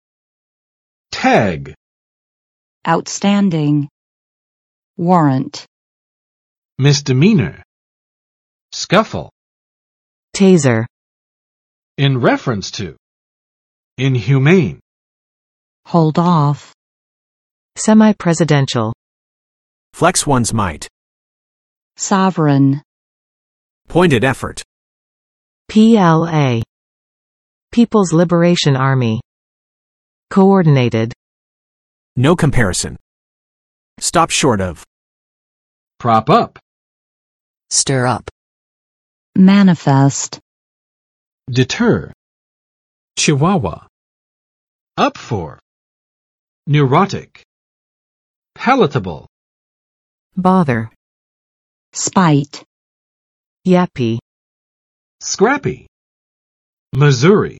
[tæg] n. 标签; 标牌